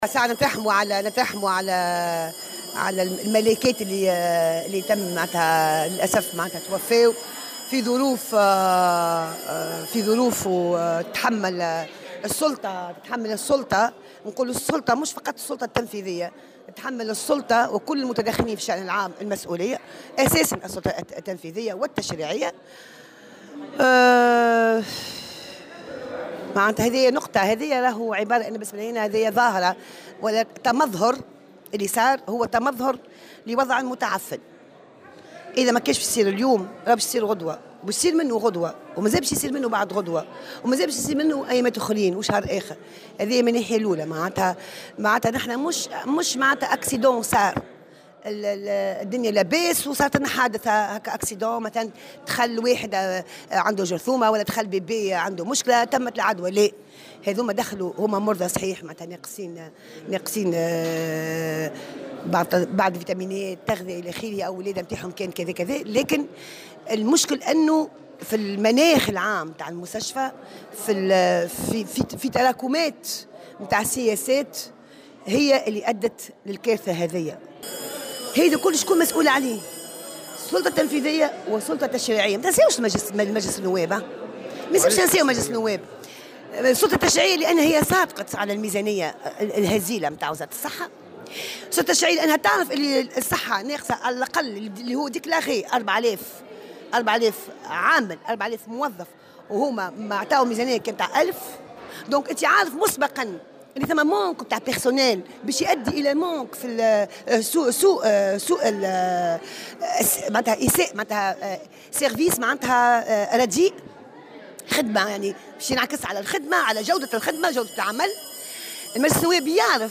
وأضافت في تصريح اليوم لمراسل "الجوهرة أف أم" أن "ما حدث تمظهر لوضع متعفن"، منتقدة ما وصفته بالإهمال و التقصير، إلى جانب ضعف الإمكانيات المادية والبشرية للمستشفيات العمومية.